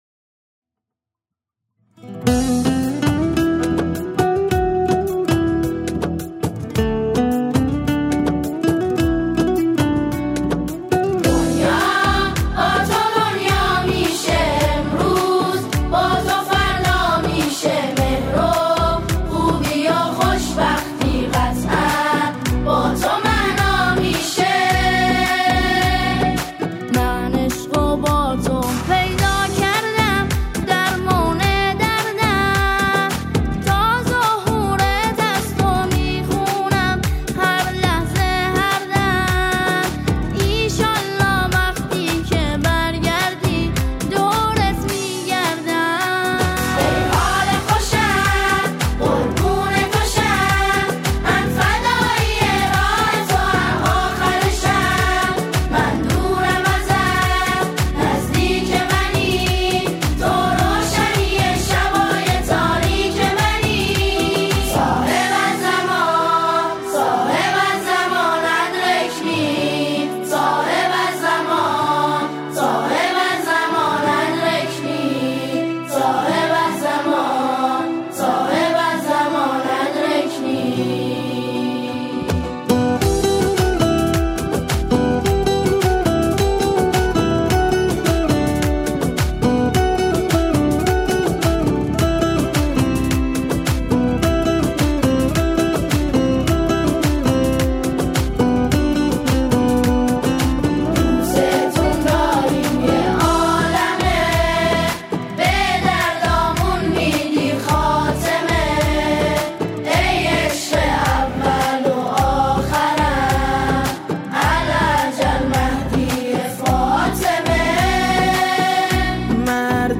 آنها در این قطعه، شعری را درباره نیمه شعبان همخوانی می‌کنند.